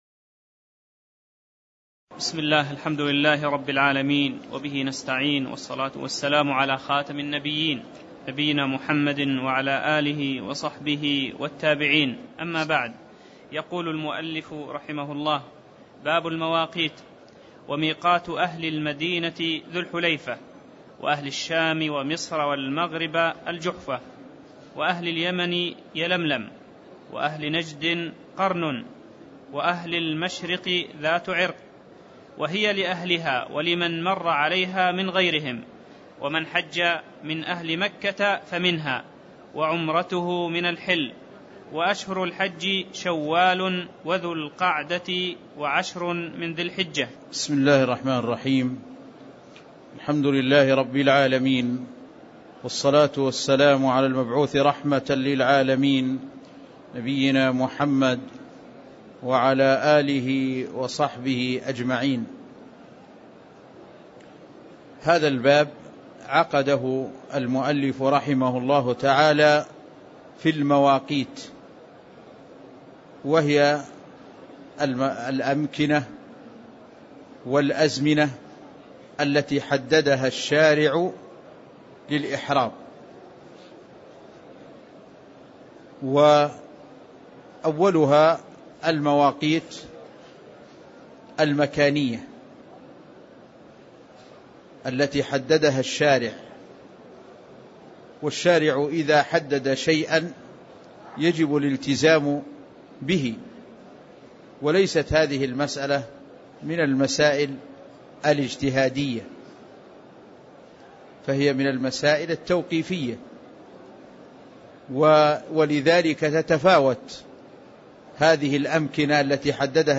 تاريخ النشر ١٦ ذو القعدة ١٤٣٥ هـ المكان: المسجد النبوي الشيخ